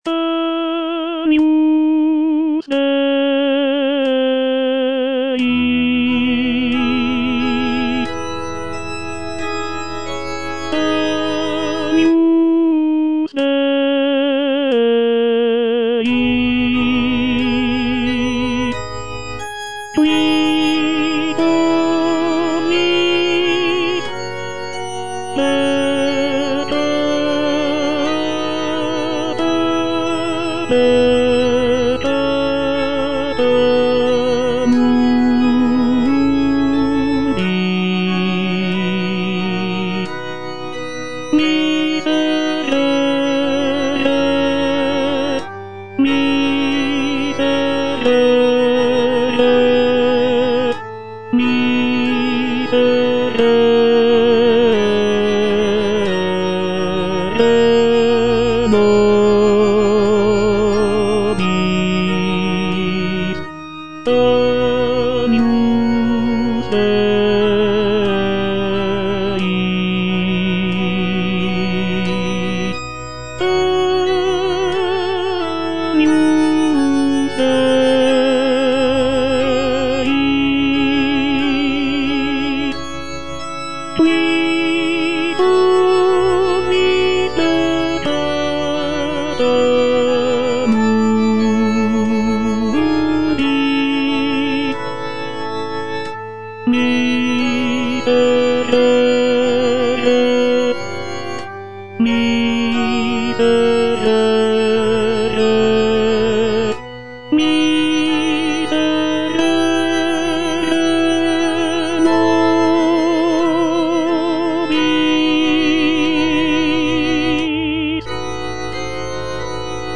J.G. RHEINBERGER - MISSA MISERICORDIAS DOMINI OP.192 Agnus Dei - Tenor (Voice with metronome) Ads stop: auto-stop
Rheinberger's composition is characterized by rich harmonies, lyrical melodies, and a blend of traditional and innovative elements.